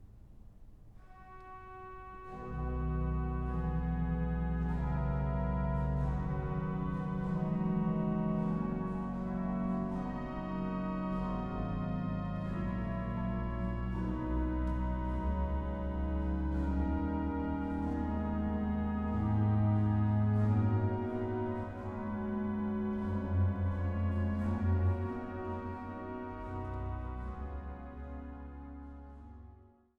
Große Sauer-Orgel der St. Johannes Kirche